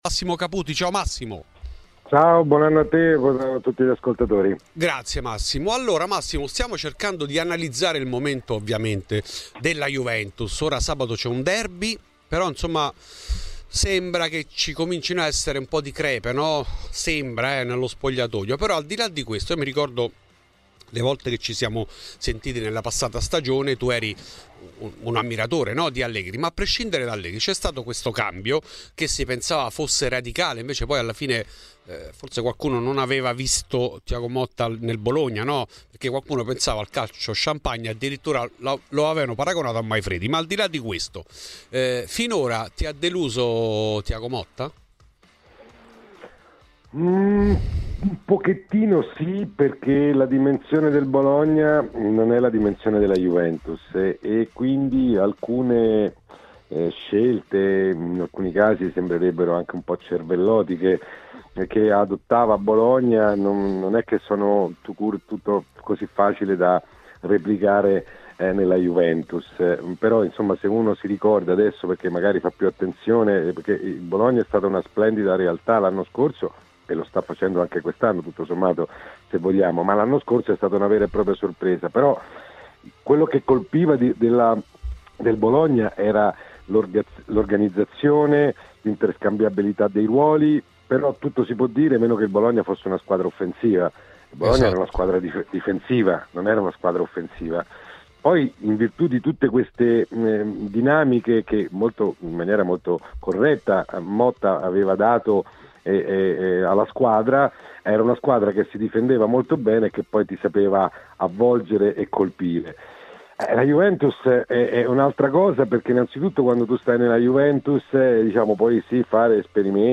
Fuori di Juve, trasmissione di Radio Bianconera